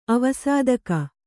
♪ avasādaka